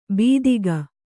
♪ bīdiga